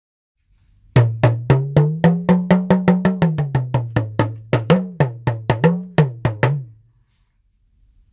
ガーナ製のトーキングドラムMサイズです。
(セネガルではタマ、ナイジェリアではドゥンドゥン、ガーナではドンド)砂時計型の両面太鼓。脇に抱えロープのテンションを変化させ、音程に高低をつけ演奏します。先が曲がった木のスティックを使用。
素材： 木 皮